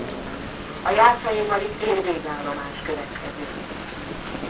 Haltestellenansagen
Budapest (Straßenbahn), Jászai Mári tér